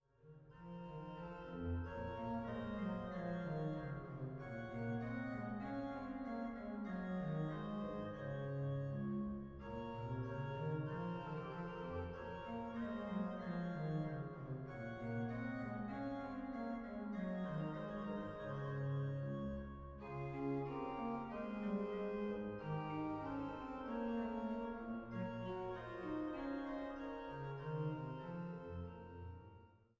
Einweihungskonzertes vom 03.11.2000